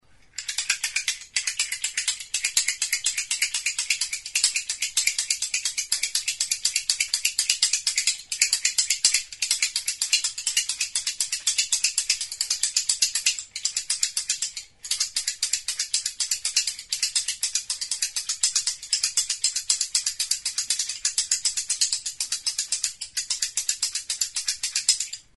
Idiophones -> Frappés -> Indirectement
Enregistré avec cet instrument de musique.
Kirtena esku tartean bi zentzuetara jiratuz, zeharka sartutako makilak alboetako hortzen kontra jotzerakoan hotsa ematen du.